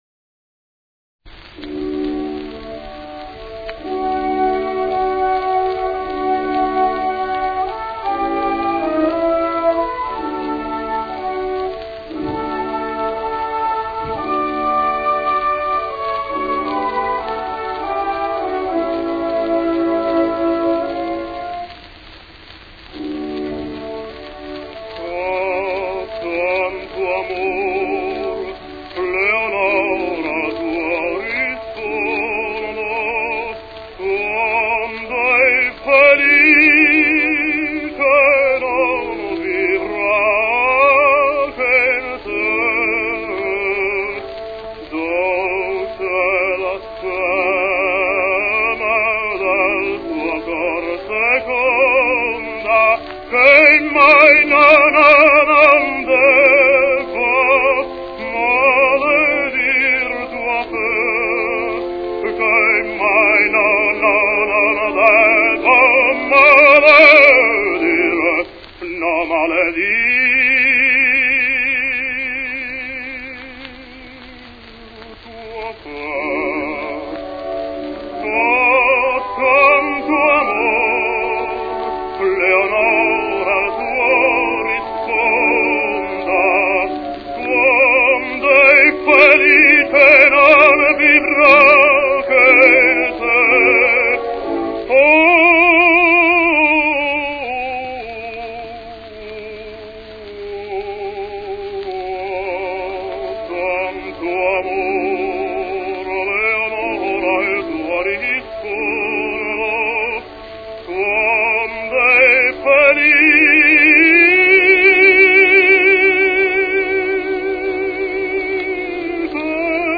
Chilean baritone and tenor, 1892 - 1935